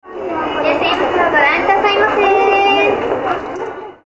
■「いらっしゃいませFEMME」は歌うように
●歌うようにNo.2●
実にメロディアス。
「No.2」は「No.1」と比べるとまだまだ青い感のある高低差の少ない歌い方だが、若さを活かした鼻声奏法と最期の伸ばしの甘えた感じが多くのファンを獲得していることと思われる。